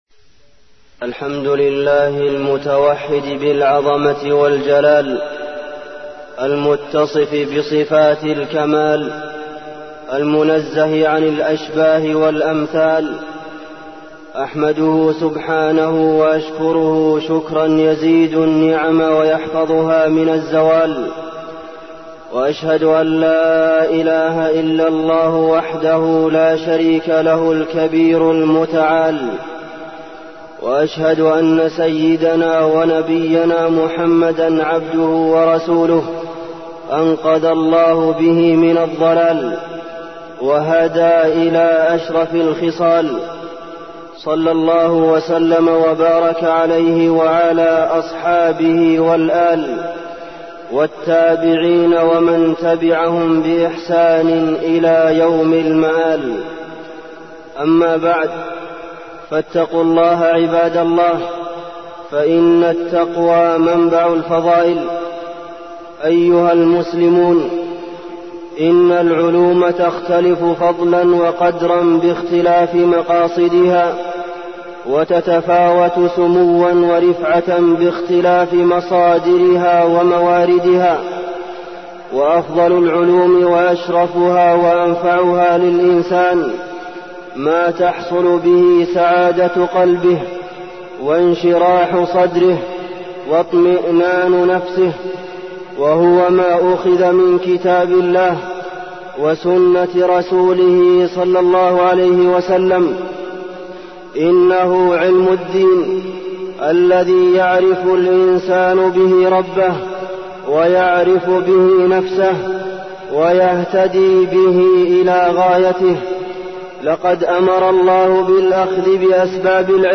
تاريخ النشر ١٣ جمادى الأولى ١٤١٩ هـ المكان: المسجد النبوي الشيخ: فضيلة الشيخ د. عبدالمحسن بن محمد القاسم فضيلة الشيخ د. عبدالمحسن بن محمد القاسم فضل العلم